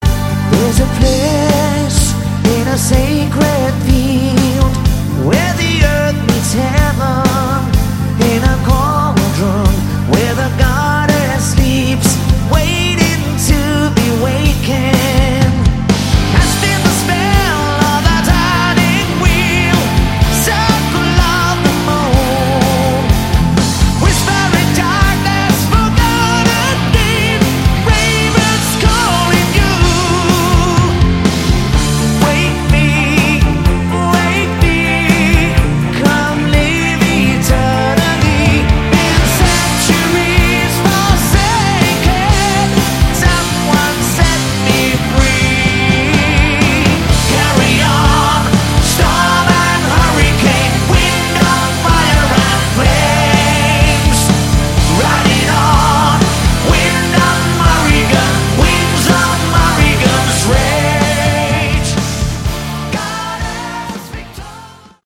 Category: Melodic Metal
vocals
guitar
bass
drums
keyboards